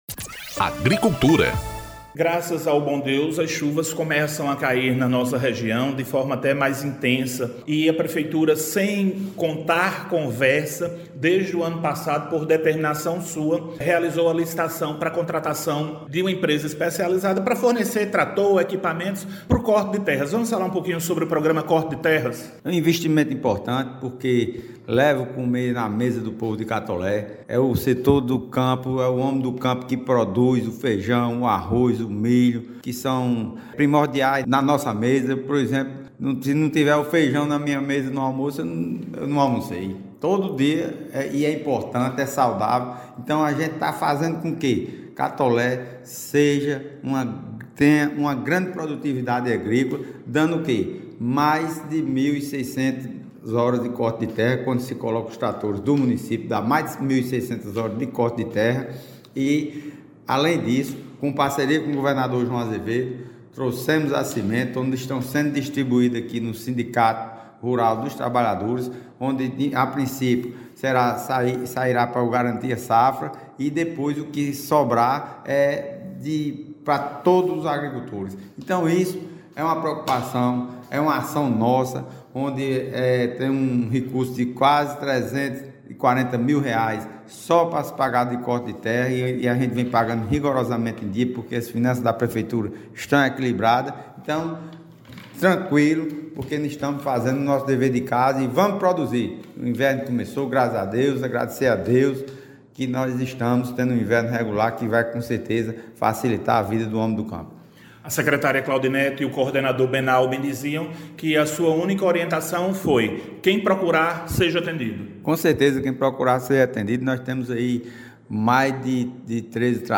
Ouça o Prefeito Laurinho Maia sobre o corte de terras no município:
F-Prefeito-Laurinho-Maia-Tema-Agricultura-Corte-de-Terras.mp3